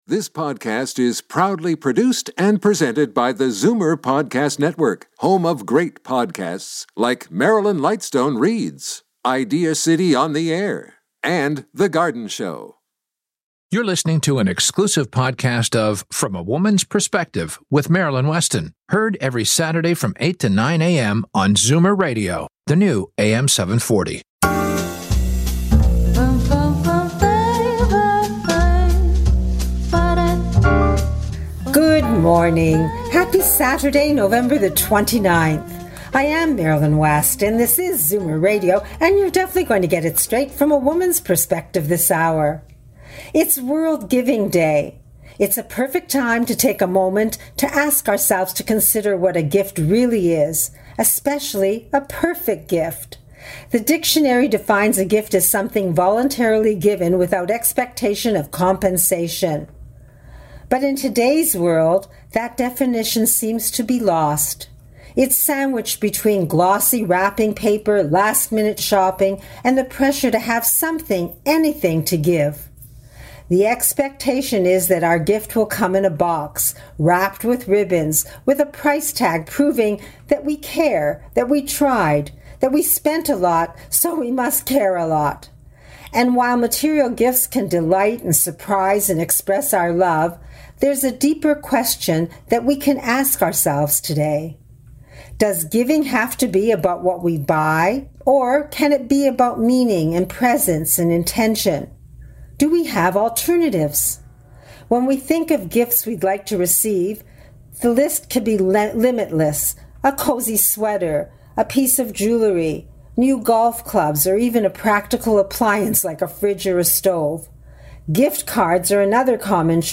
weekly interview program